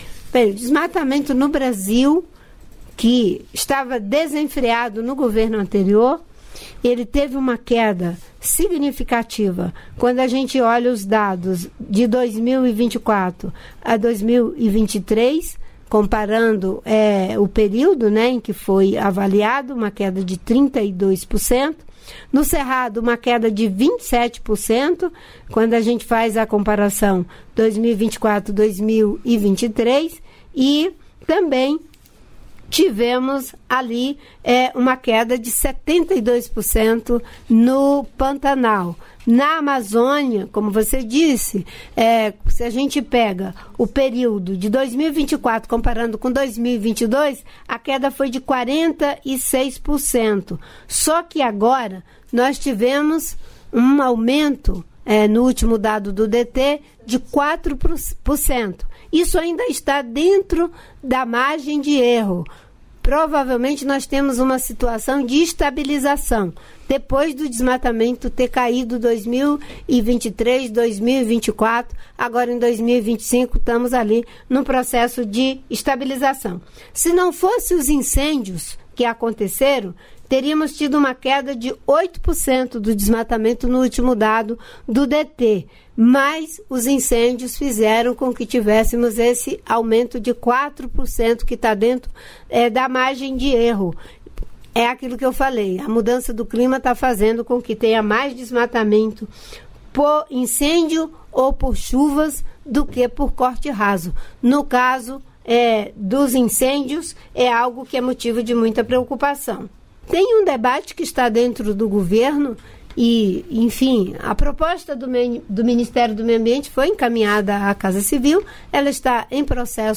Trecho da participação da ministra do Meio Ambiente e Mudança do Clima, Marina Silva, no programa "Bom Dia, Ministra" desta quinta-feira (14), nos estúdios da EBC em Brasília (DF).